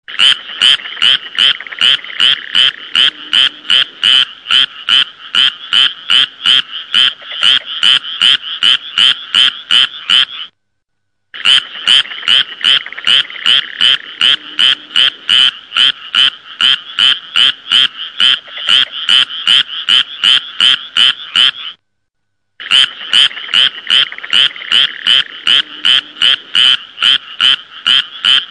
Frog Call klingelton kostenlos
Kategorien: Tierstimmen
Frog-Call.mp3